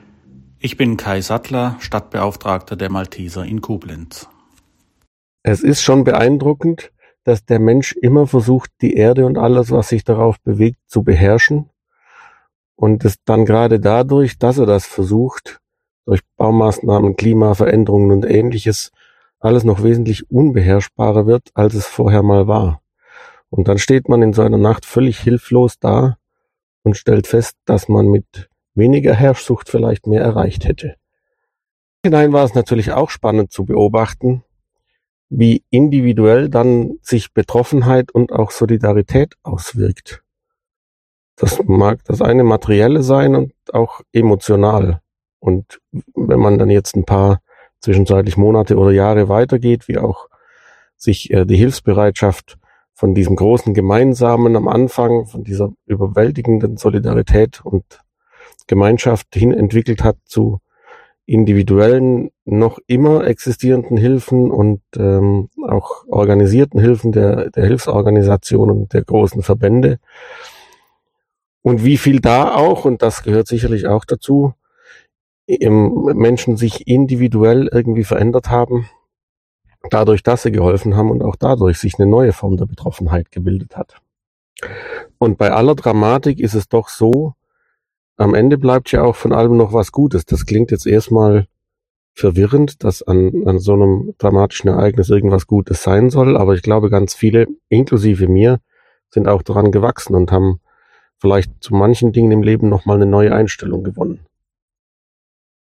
Sechs von ihnen teilen ihre persönlichen Eindrücke mit uns, sprechen ungefiltert und offen von ihren Erfahrungen, die sie bis heute begleiten.